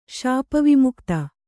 ♪ śapa vimukta